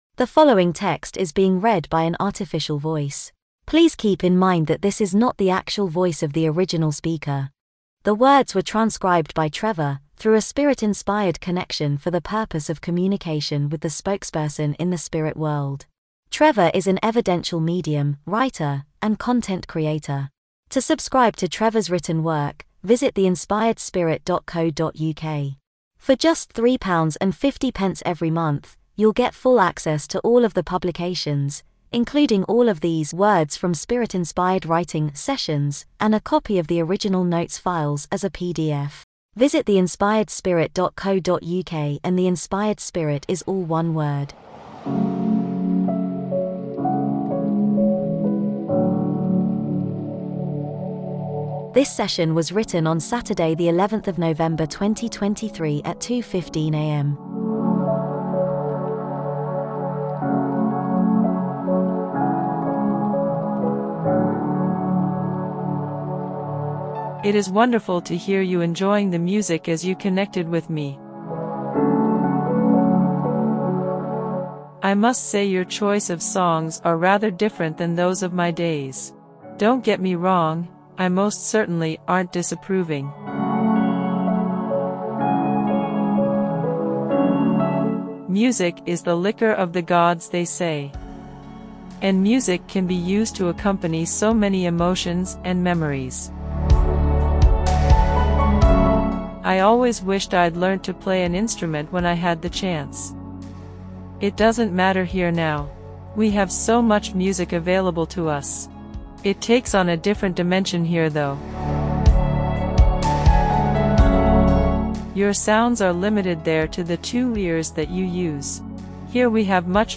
AI AUDIO VERSION OF THIS SESSION